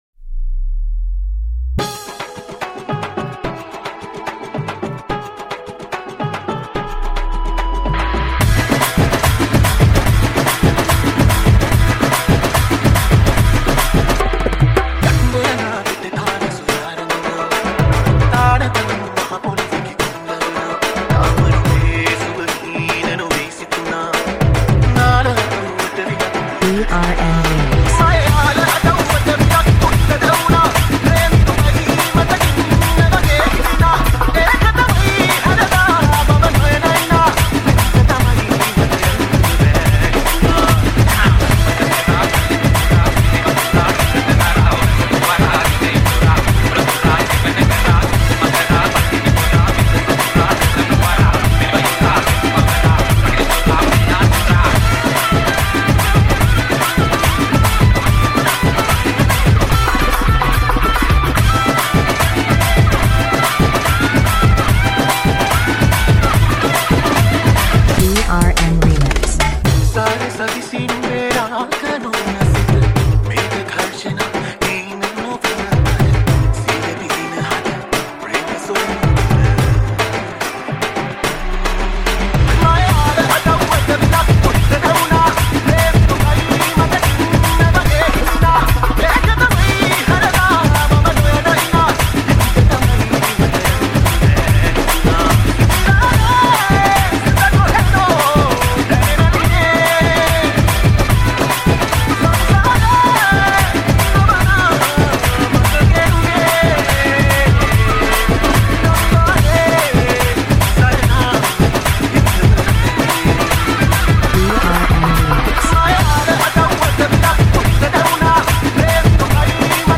6-8 Dance Remix